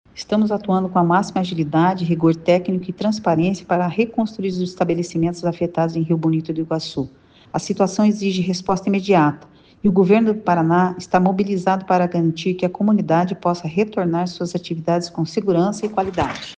Sonora da diretora-presidente do Fundepar, Eliane Teruel Carmona, sobre o edital de R$ 16 milhões para reconstrução de prédios educacionais em Rio Bonito do Iguaçu